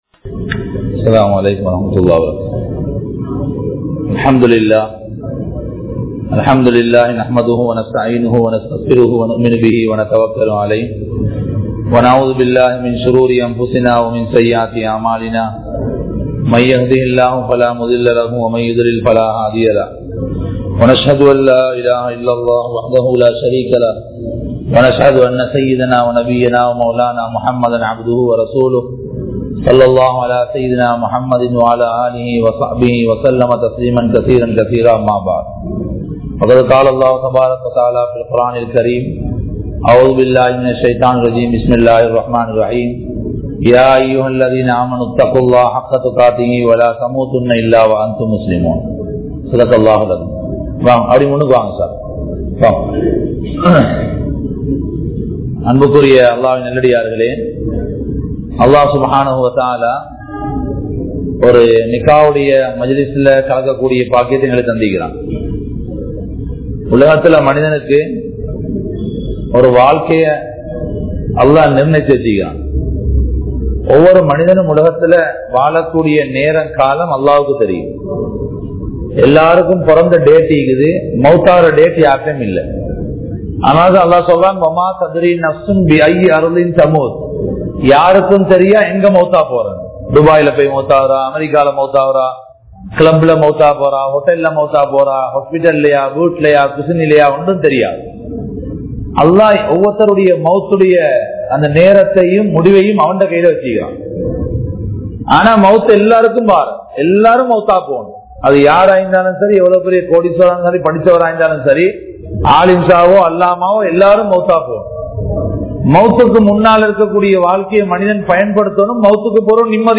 Nimmathi Illaatha Kanavanum Manaivium (நிம்மதியில்லாத கணவனும் மனைவியும்) | Audio Bayans | All Ceylon Muslim Youth Community | Addalaichenai
Majma Ul Khairah Jumua Masjith (Nimal Road)